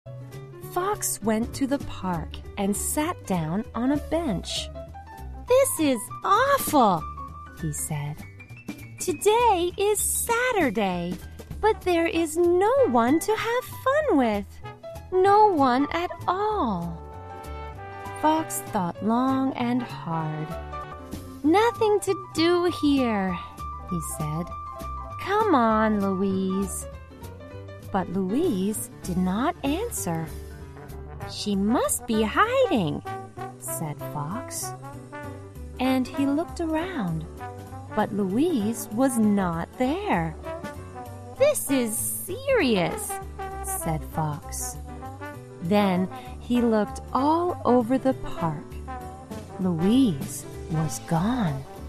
在线英语听力室小狐外传 第3期:公园的长凳的听力文件下载,《小狐外传》是双语有声读物下面的子栏目，非常适合英语学习爱好者进行细心品读。故事内容讲述了一个小男生在学校、家庭里的各种角色转换以及生活中的趣事。